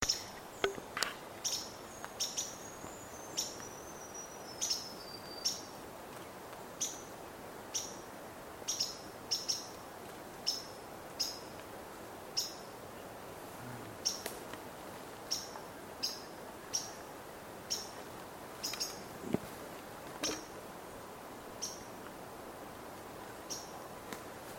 Rufous-breasted Leaftosser (Sclerurus scansor)
tres ejemplares observados
Location or protected area: Parque Provincial Esmeralda
Condition: Wild
Certainty: Photographed, Recorded vocal